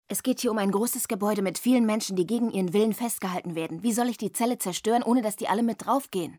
For the video game of one of America’s most successful TV series, Effective Media was able to employ the entire German voice-over cast along with the director.